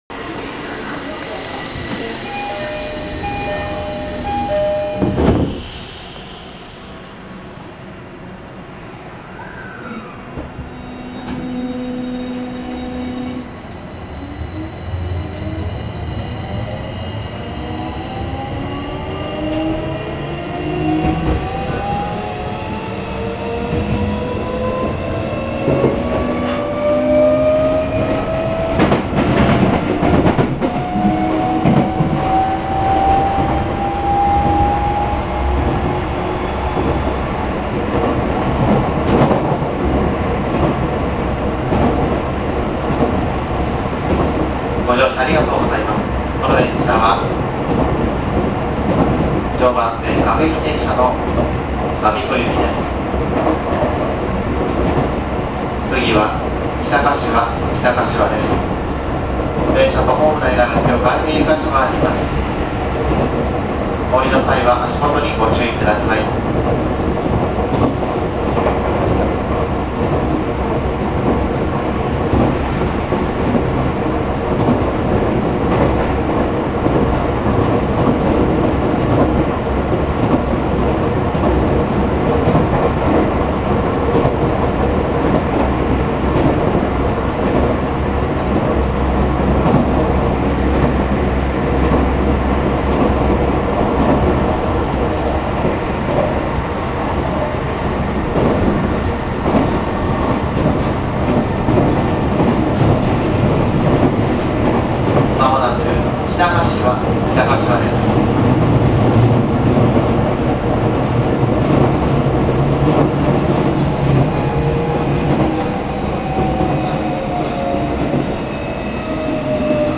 ・16000系2次車まで走行音
【JR常磐線】柏〜北柏（2分28秒：809KB）
今までの車両に無かった走行音が目立っています。